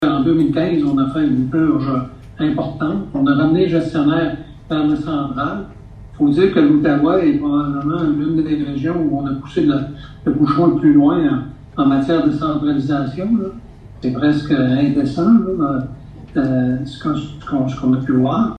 Hier soir a eu lieu la conférence Mieux comprendre la réalité rurale pour assurer l’accessibilité aux soins de santé au Château Logue de Maniwaki.